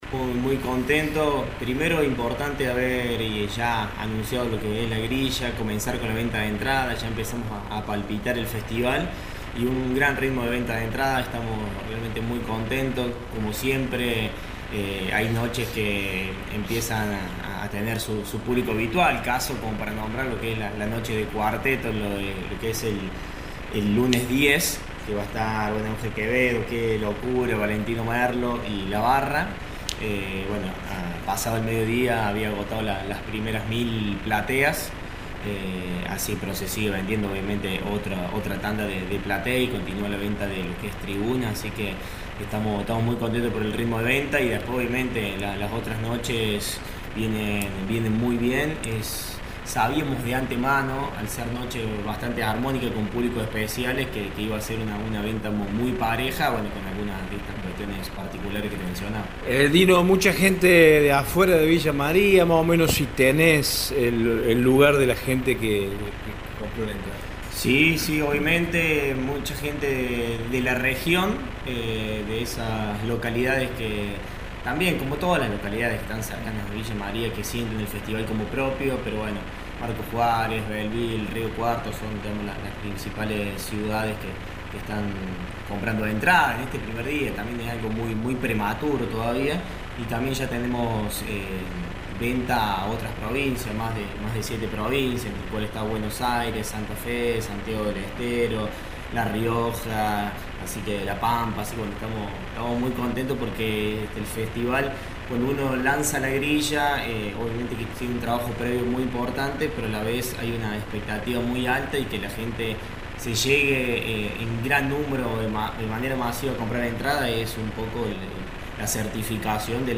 El director de Turismo, Dino Gagliese, dialogó con Radio Show al respecto.